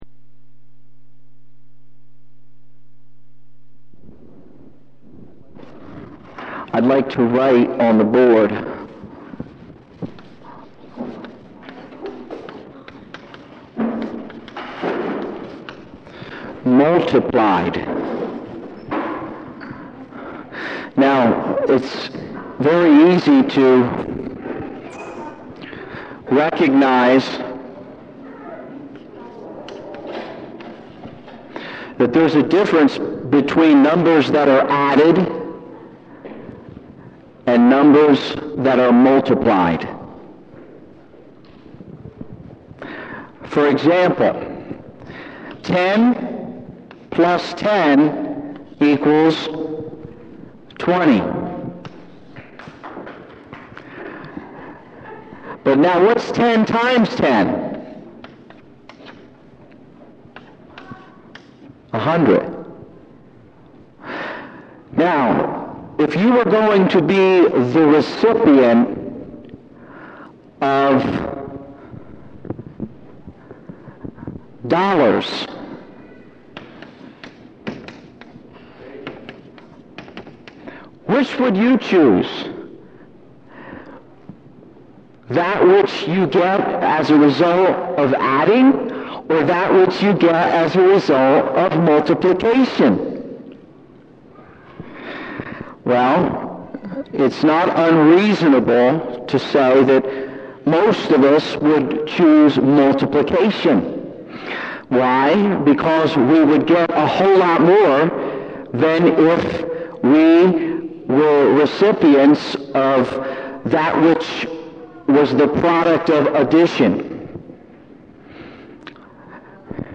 SermonIndex